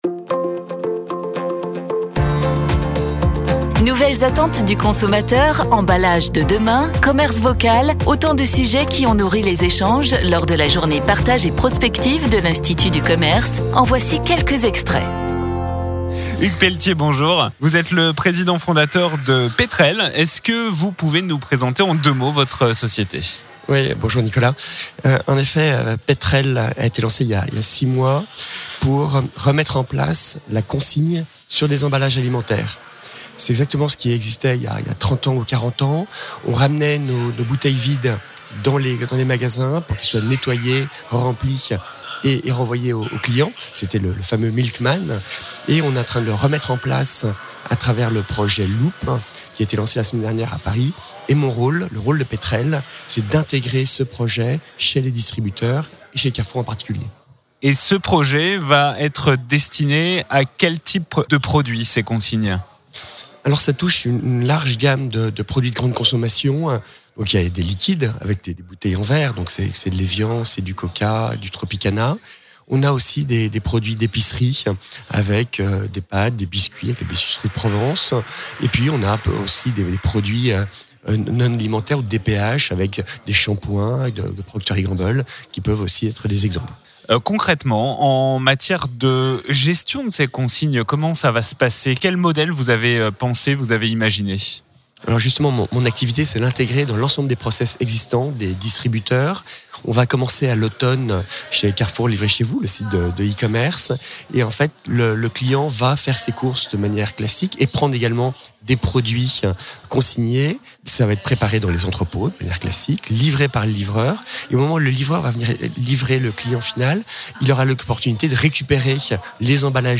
Les interviews Mediameeting de la Journée Partage et Prospective 2019